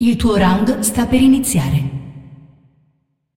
vo-anncr-fem1-tournaments-round-start-01.ogg